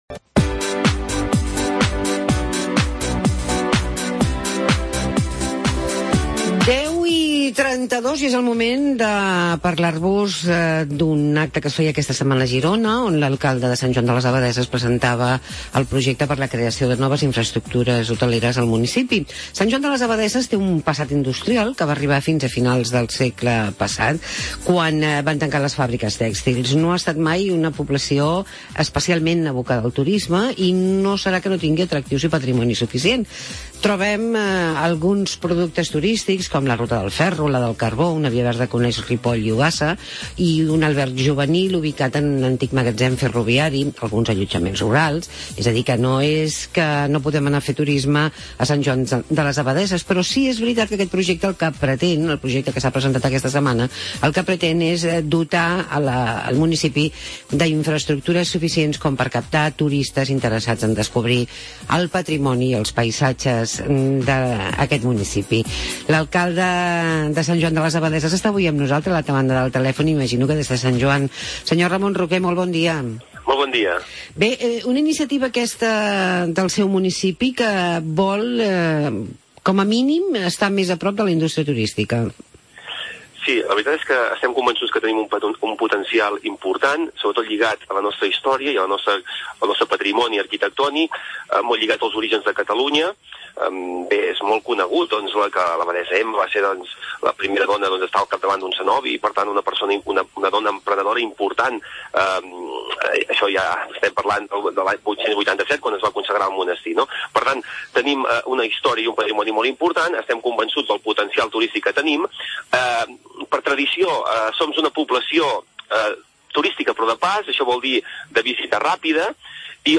Entrevistem a Ramón Roquè, alcalde de Sant Joan de les Abadesses